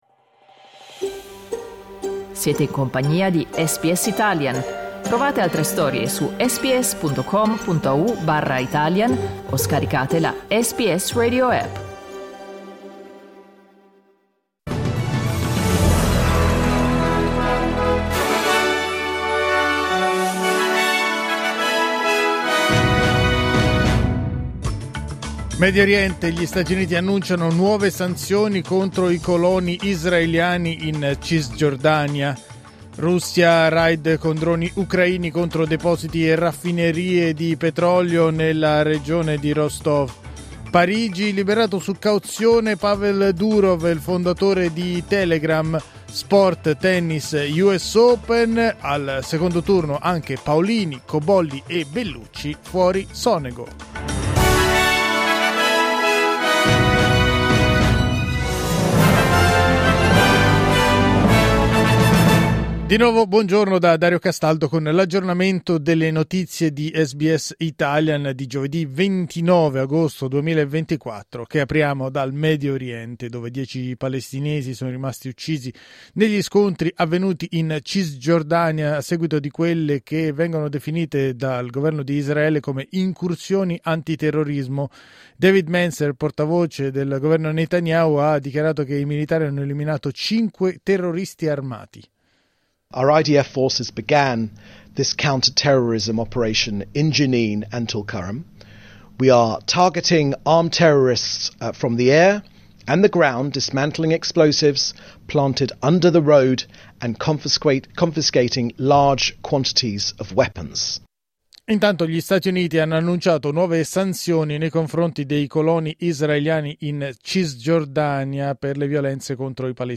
News flash giovedì 29 agosto 2024